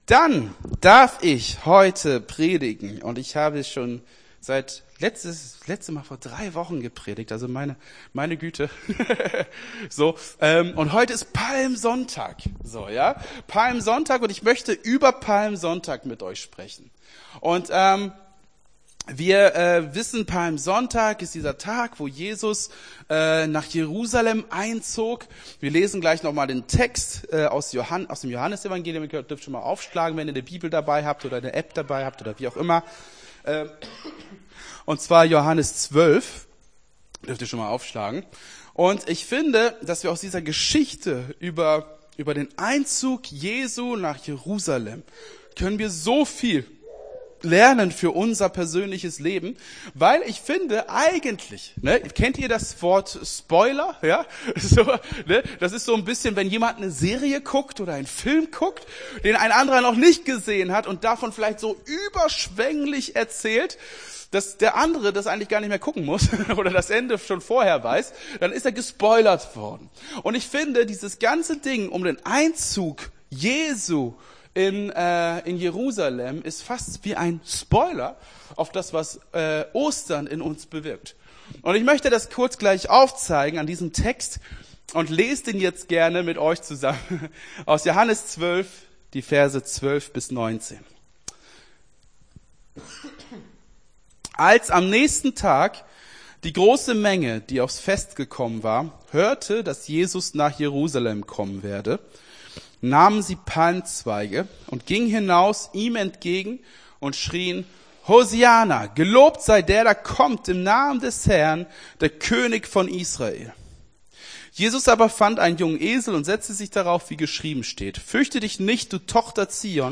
Gottesdienst 24.03.24 - FCG Hagen